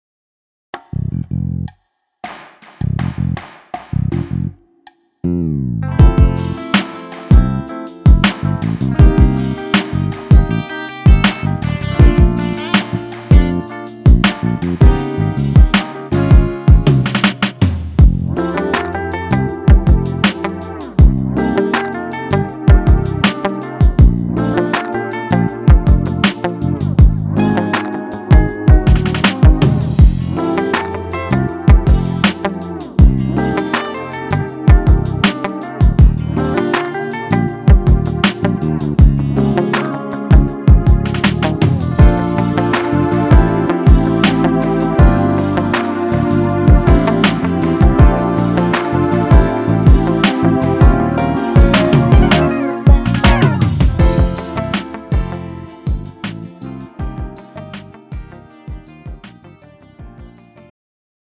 음정 -1키
장르 가요 구분 Pro MR